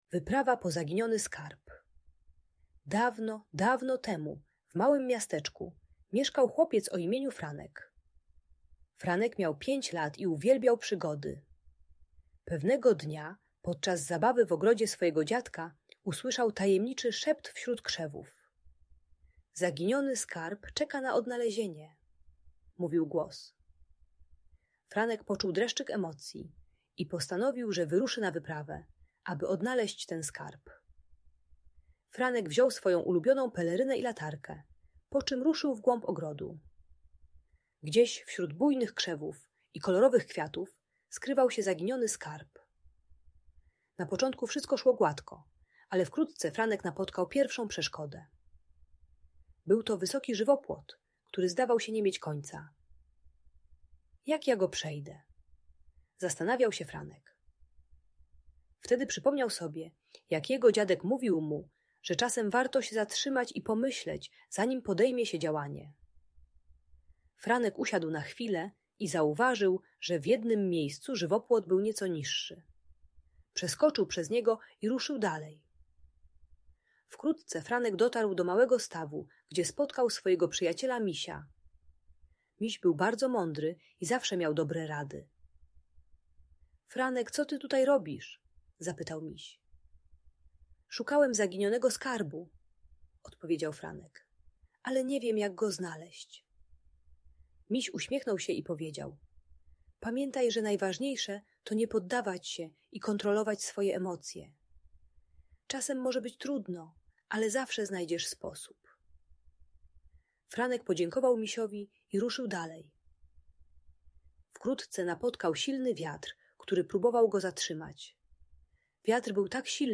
Wyprawa po Zaginiony Skarb - Agresja do rodziców | Audiobajka